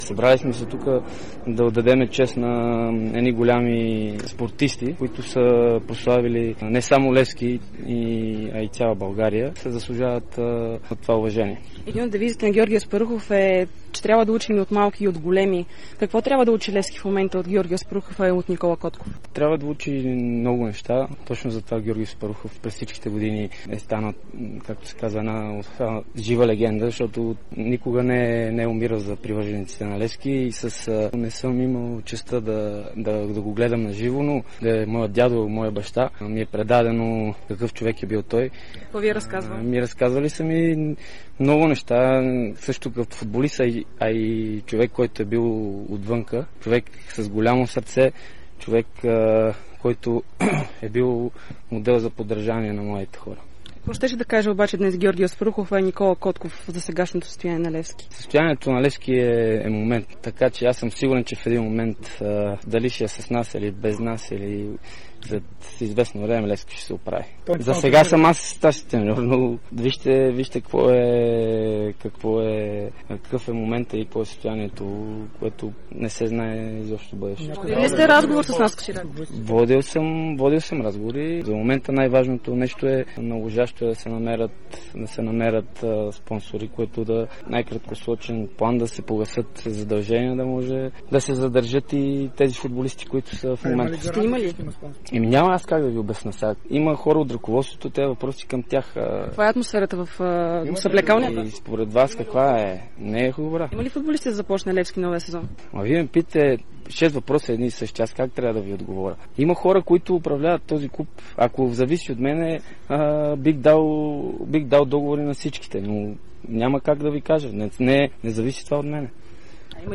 Старши треньорът на Левски Живко Миланов говори след панихидата в памет на Георги Аспархухов и Никола Котков. Той заяви, че би предложил договори на всички футболисти в тима, ако зависи от него. Въпреки тежкото състояние Миланов е оптимист за бъдещето на клуба, като сподели, че най-важното е да се намерят спонсори в най-краткосрочен план.